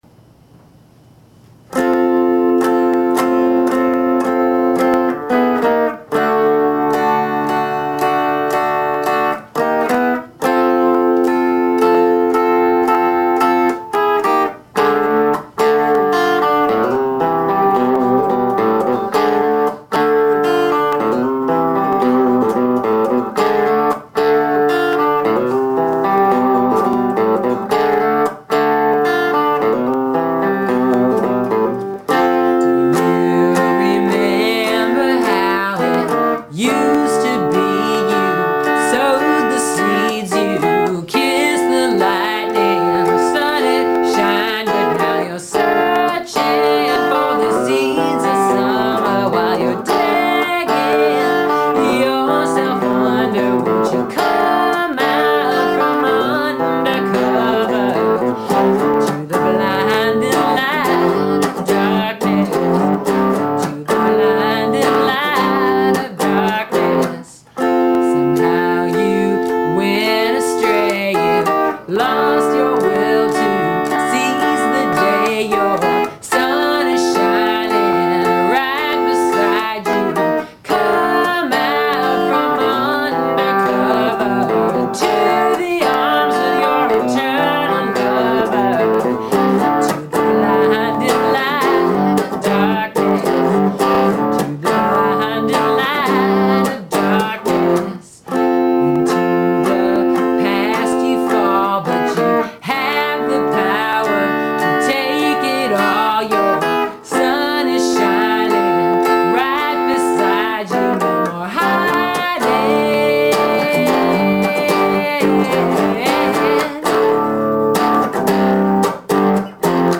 playing into my video camera during one of our practices